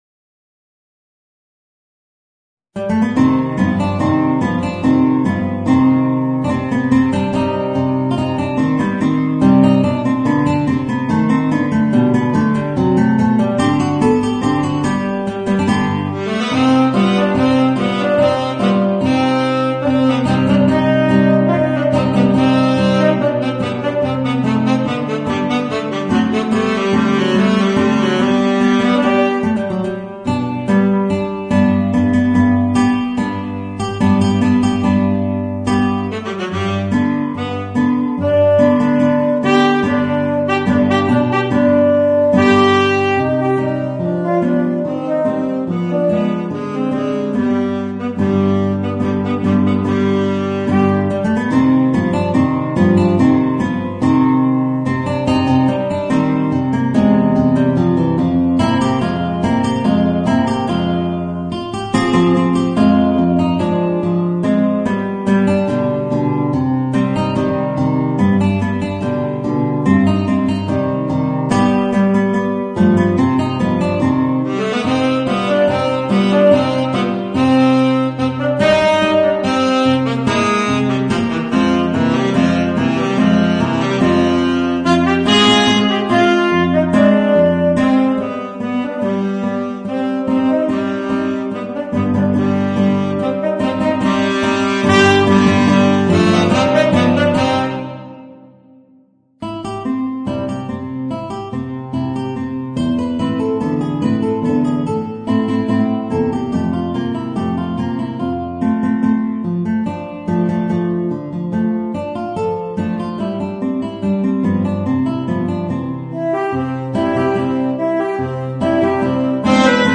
Voicing: Guitar and Tenor Saxophone